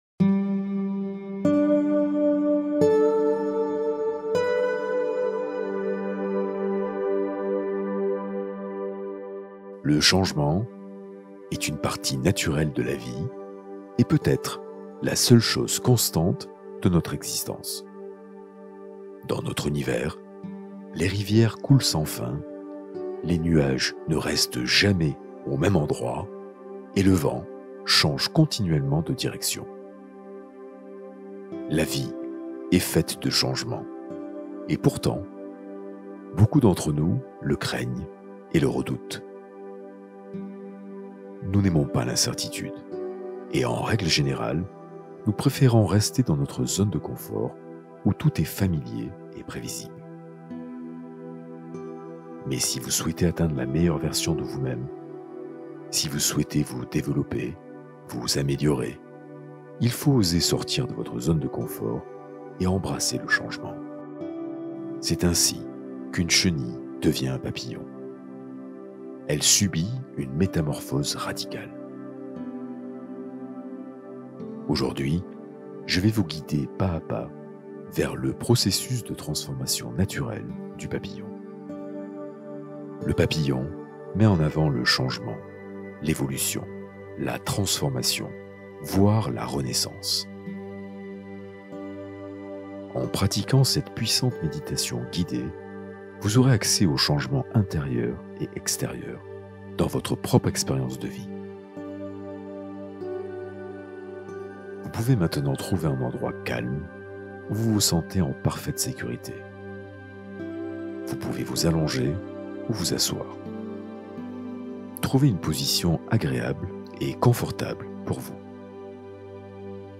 Création consciente : méditation de reprogrammation mentale profonde